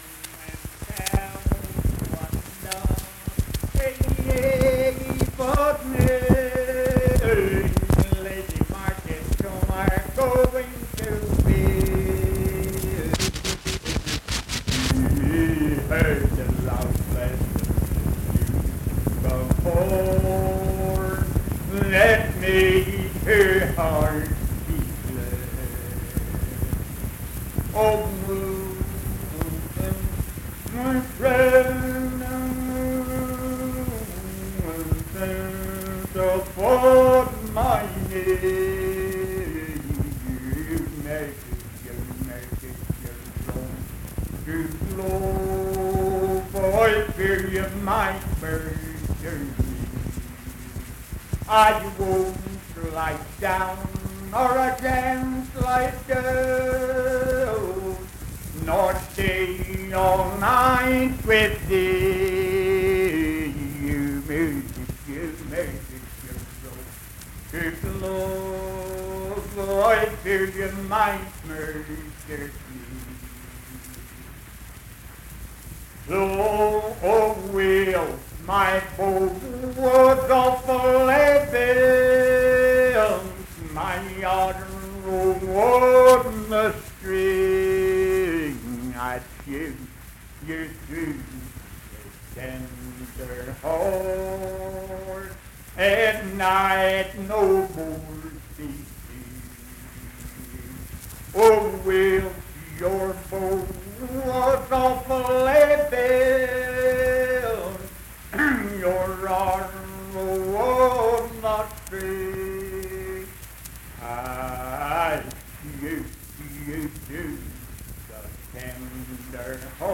Unaccompanied vocal music performance
Verse-refrain 7(4).
Voice (sung)